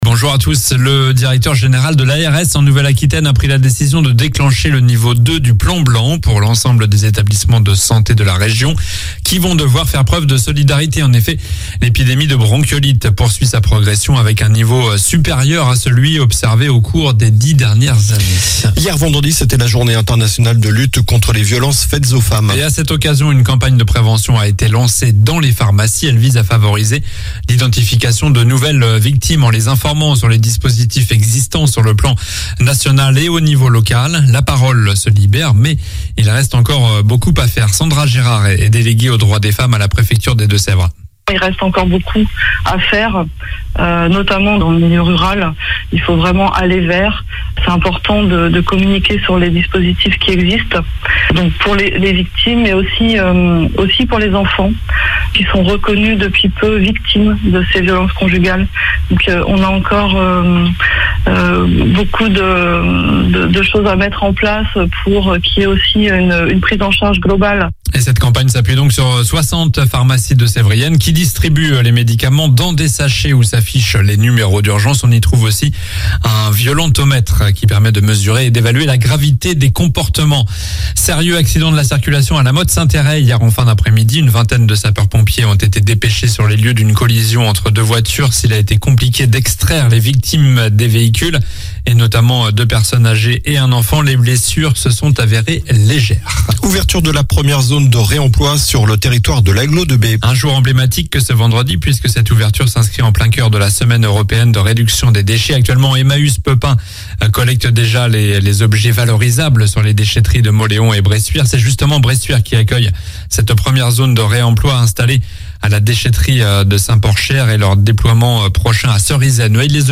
Journal du samedi 26 novembre (matin)